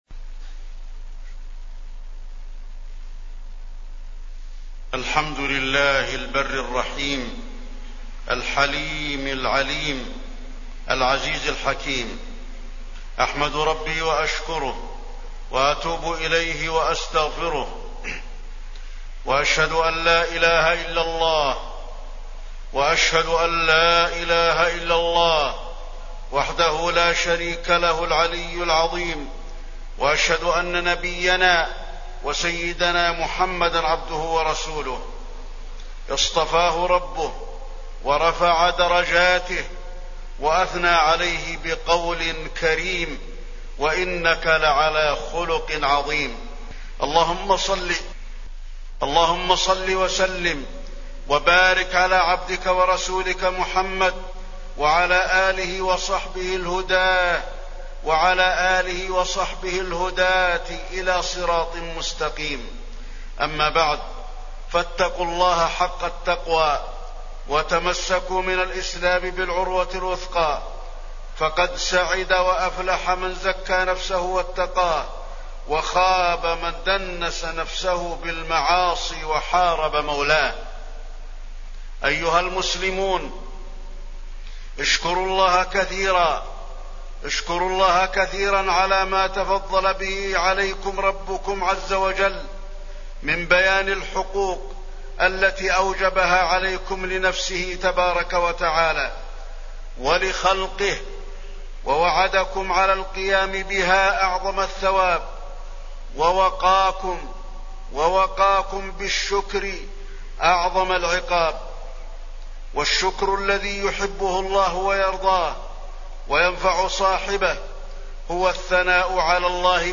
تاريخ النشر ١٩ جمادى الآخرة ١٤٣٠ هـ المكان: المسجد النبوي الشيخ: فضيلة الشيخ د. علي بن عبدالرحمن الحذيفي فضيلة الشيخ د. علي بن عبدالرحمن الحذيفي صلة الرحم